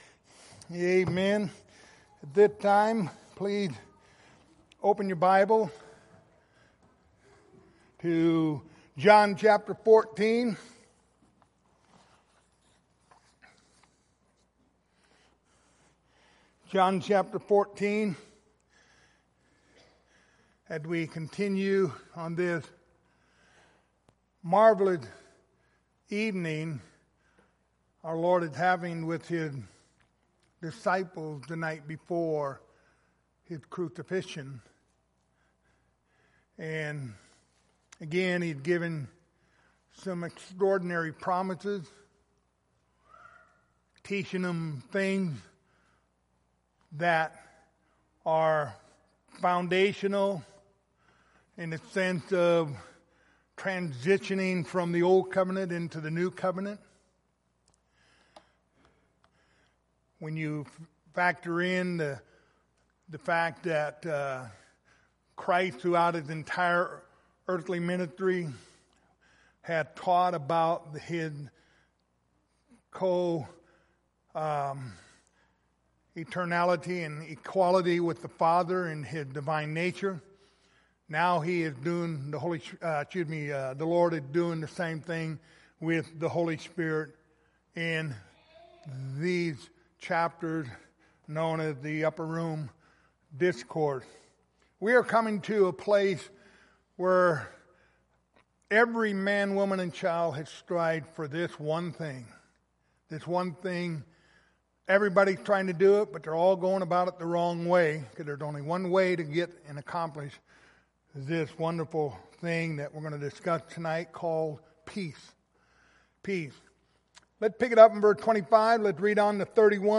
Passage: John 14:25-31 Service Type: Wednesday Evening Topics